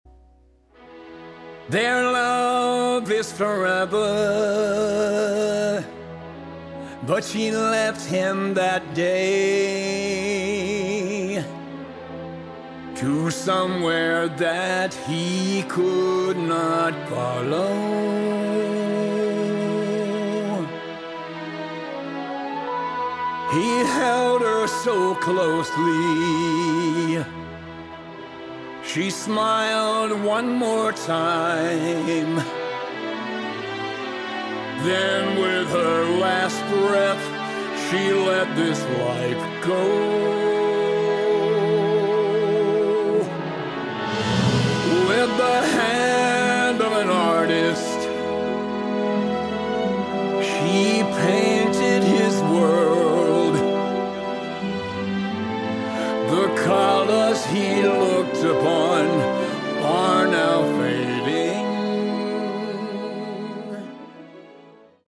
Category: Hard Rock
vocals